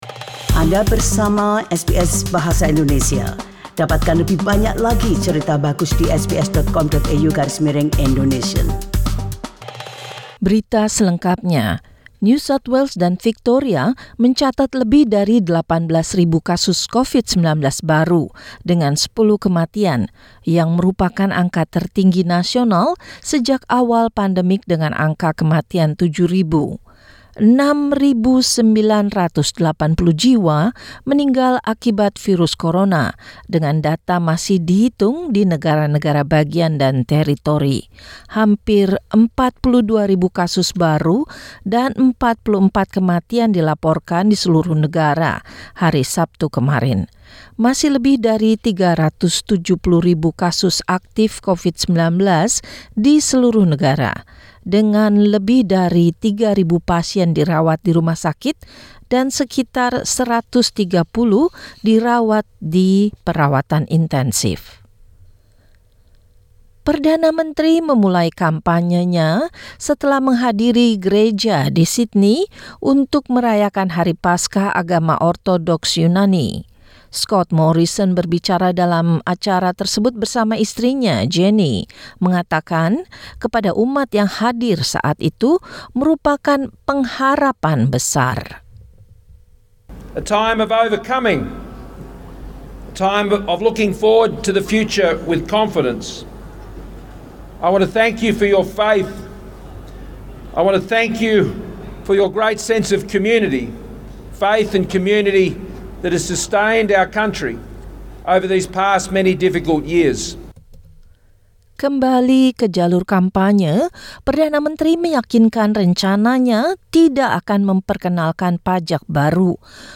Warta Berita Radio SBS yang disampaikan dalam Bahasa Indonesia - Minggu, 24 April 2022.